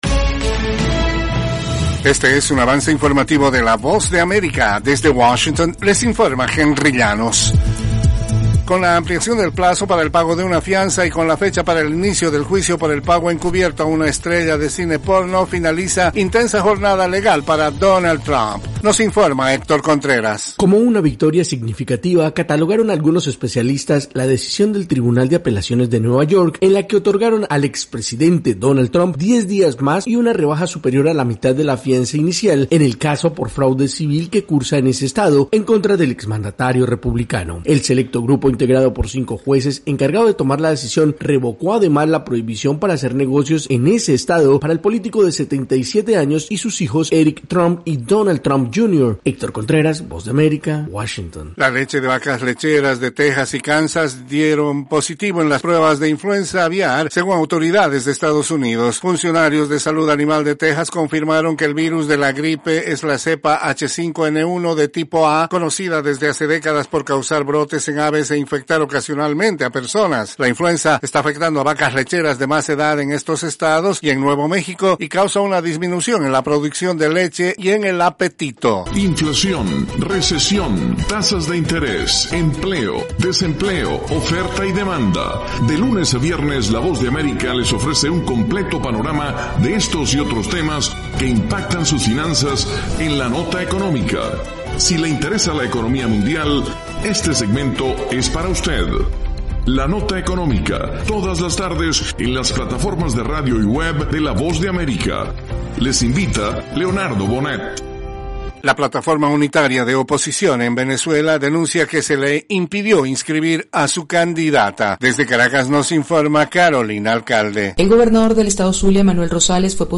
Cápsula informativa de tres minutos con el acontecer noticioso de Estados Unidos y el mundo.
Desde los estudios de la Voz de América en Washington